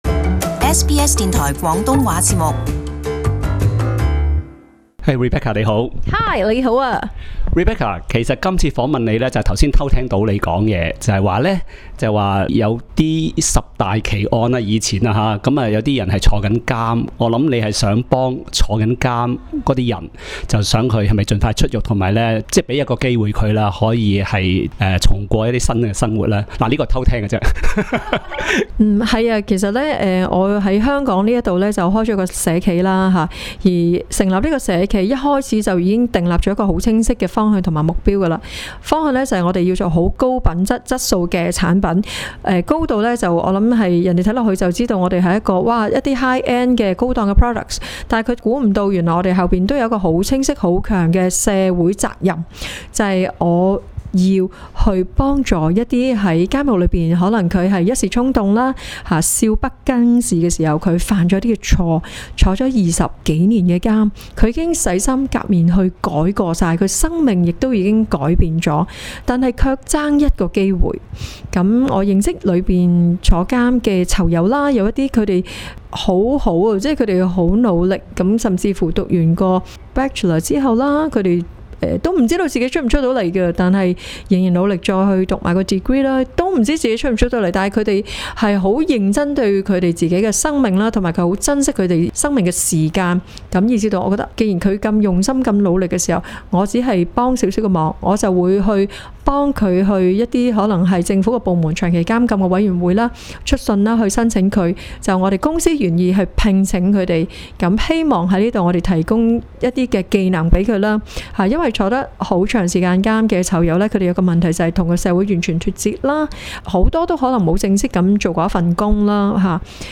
【社團專訪】